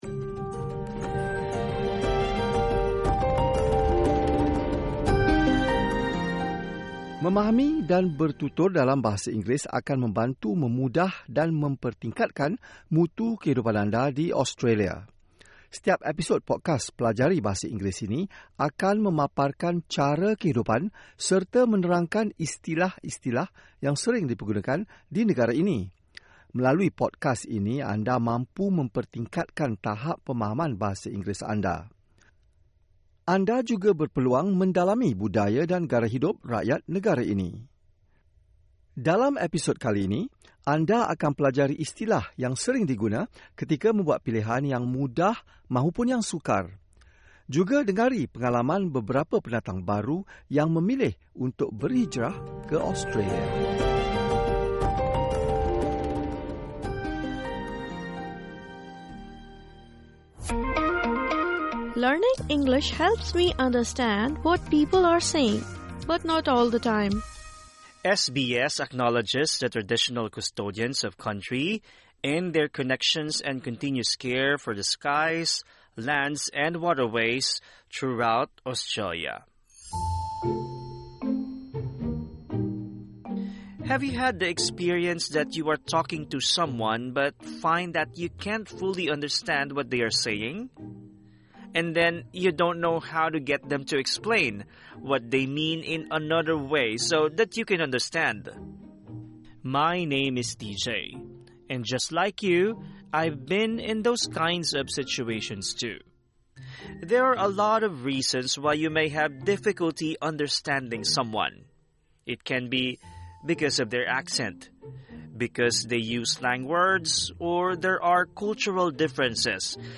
Pelajari bagaimana untuk memperkatakan “Saya Tidak Faham”. Anda juga berpeluang mendengar seorang pelawak menceritakan kisah lucu yang timbul akibat tersalah faham sebuah pepatah yang sering digunakan di Australia.